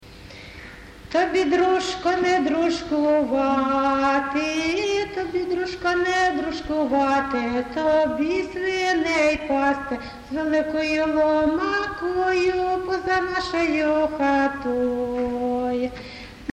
ЖанрВесільні
Місце записус-ще Зоря, Краматорський район, Донецька обл., Україна, Слобожанщина